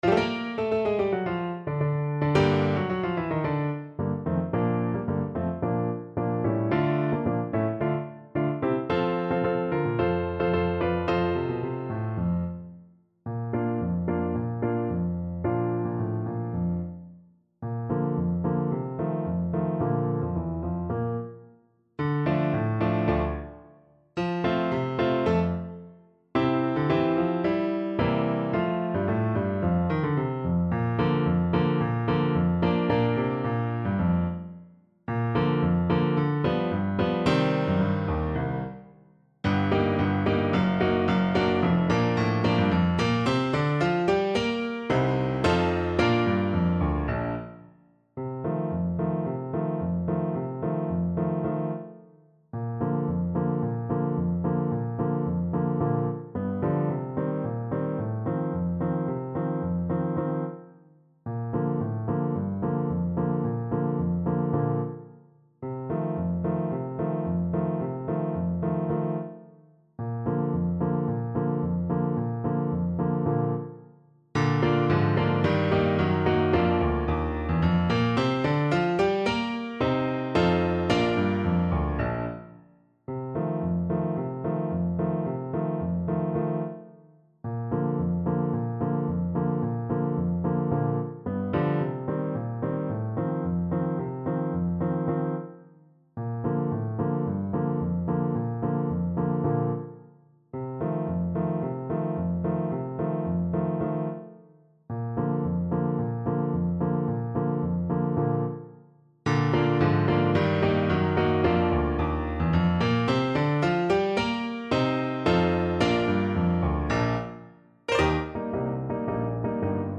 Classical (View more Classical Trumpet Music)